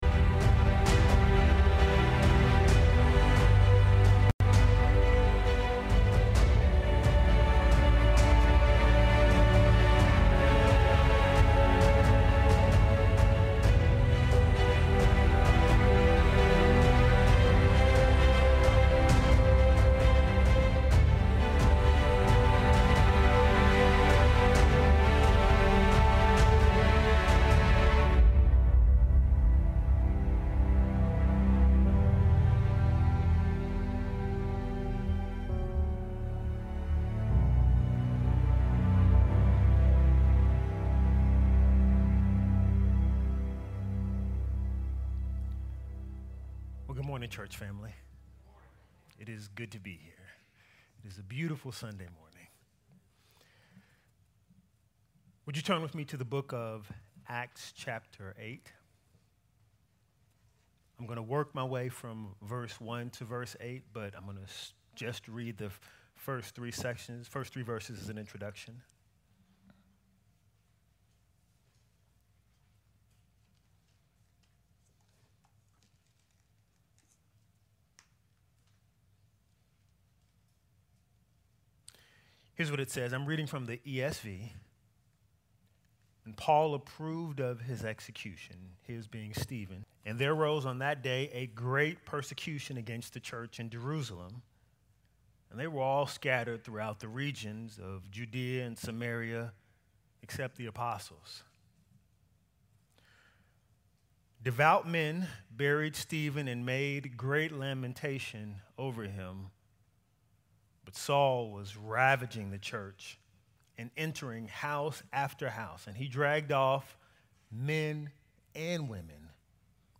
Acts of the Apostles Acts 8 1 – 7 | June 4 2023 | TBC Lexington | Trinity Baptist Church